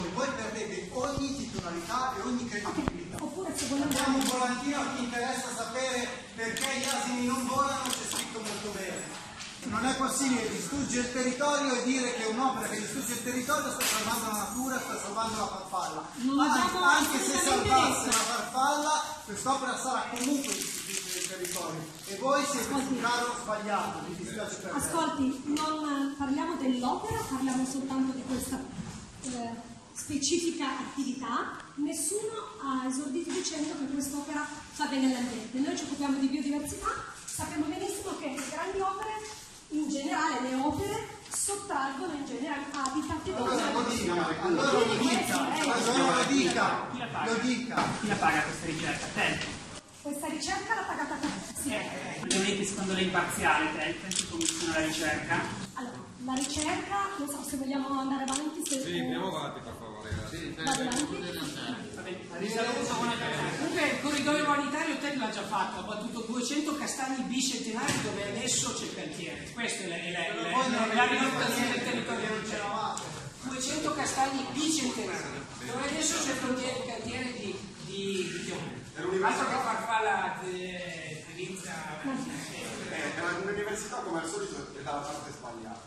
Il nostro intervento durante la conferenza stampa